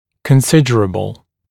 [kən’sɪdərəbl][кэн’сидэрэбл]значительный, существенный